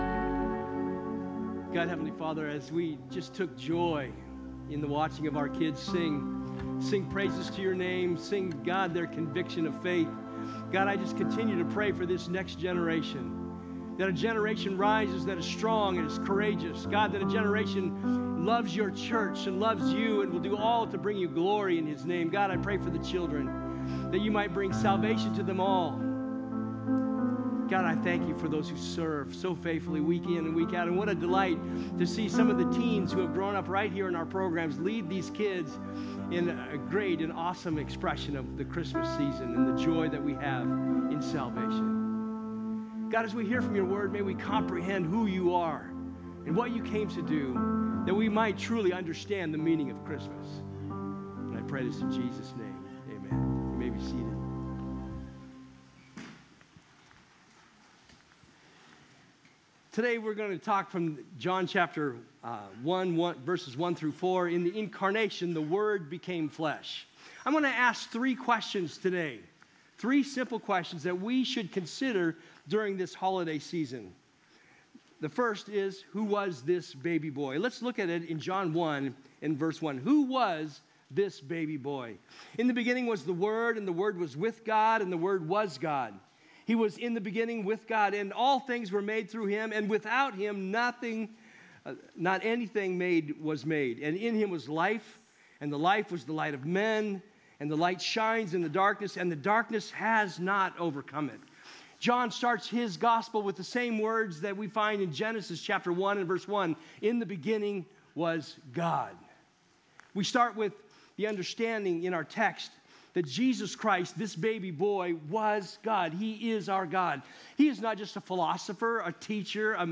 Join us in a sermon in John!